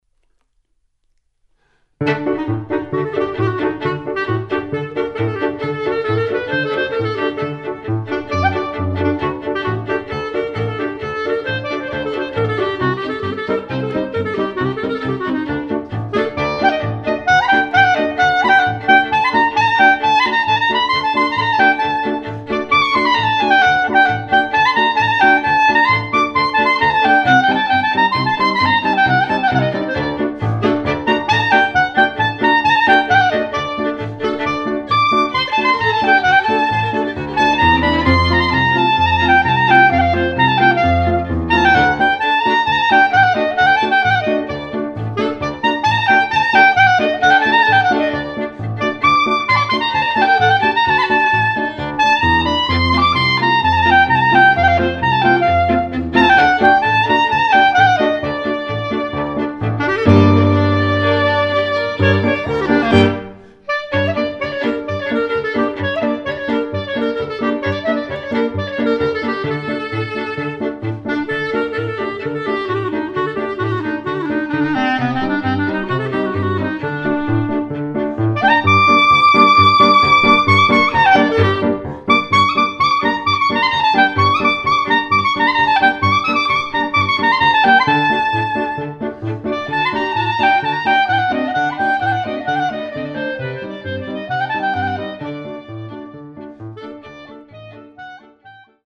at Jack Straw Studios in Seattle
klezmer music